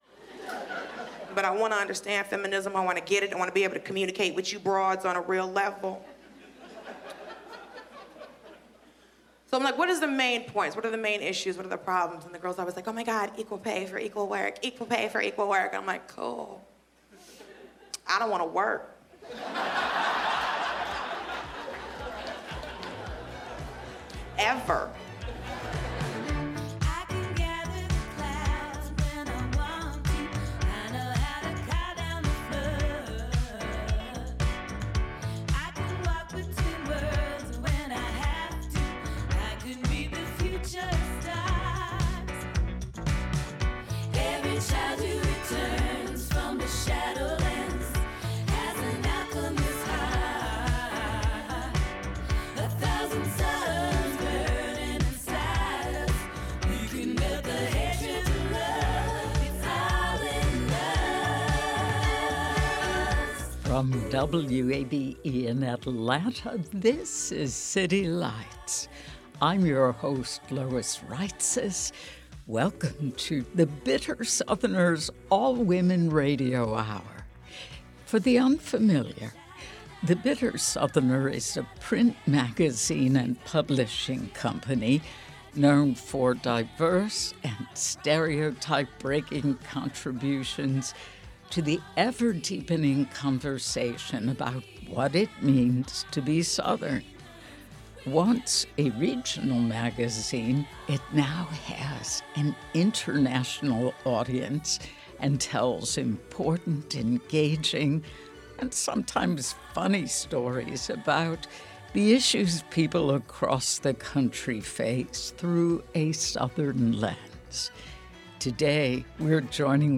WABE and The Bitter Southerner partner to bring the magazine's All Women Issue to life through storytelling, song, poetry, and comedy.